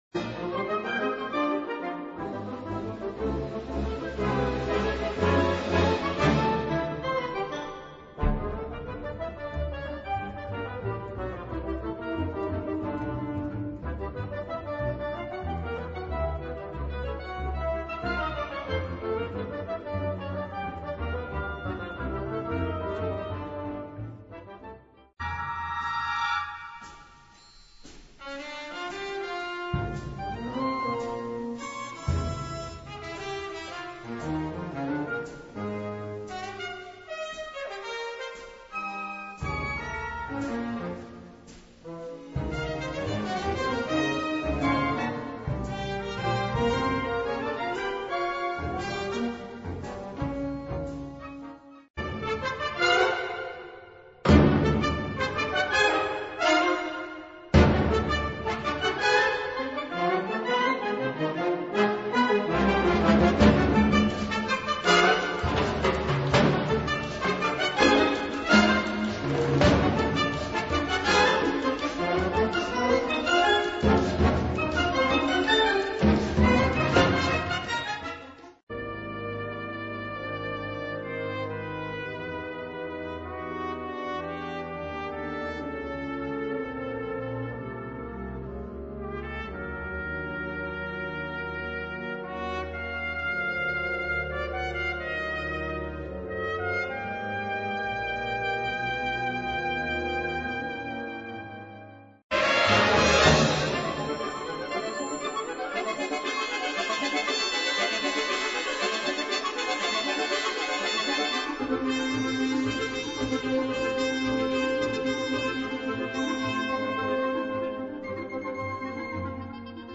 Categorie Harmonie/Fanfare/Brass-orkest
Subcategorie Hedendaagse blaasmuziek (1945-heden)
Bezetting Ha (harmonieorkest)
I. Ragtime (Joyously, but not to fast)
II. Blues
III. Mambo Loco (Rhythmic - with emotions)
V. The March of the Little People (Brightly - with humor)